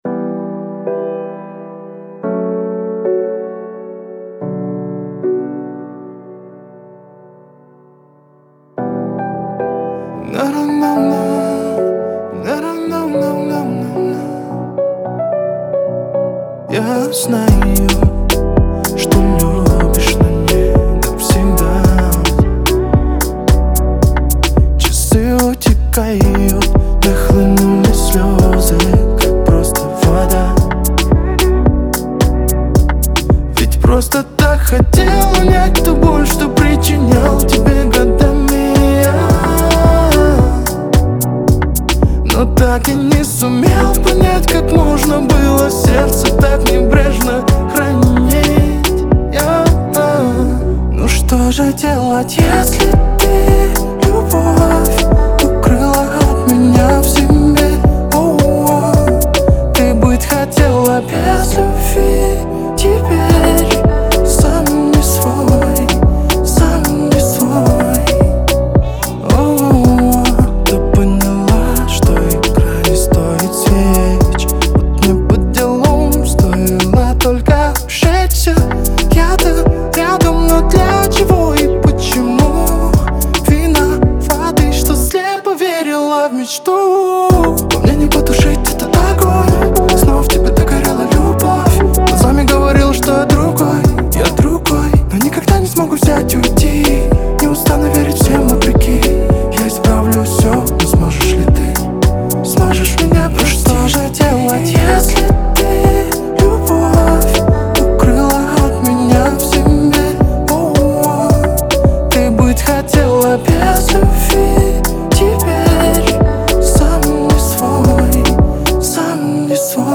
Трек размещён в разделе Русские песни / Поп / 2022.